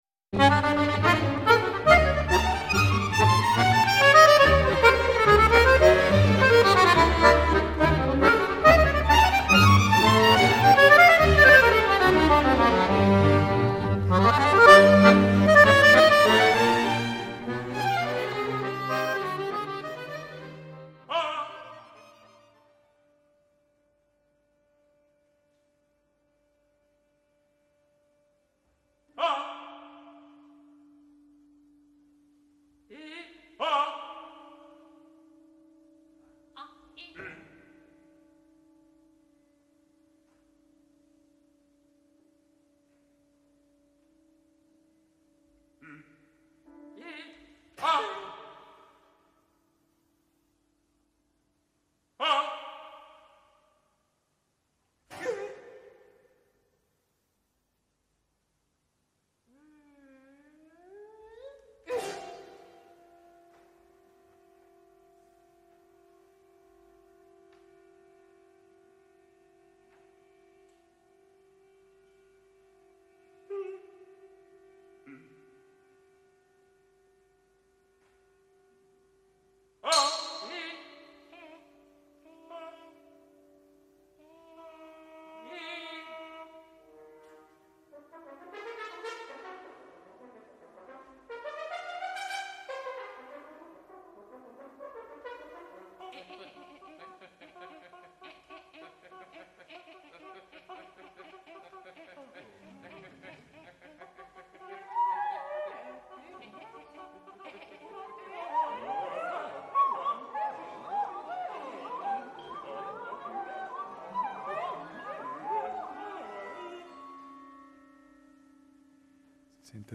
Musicalbox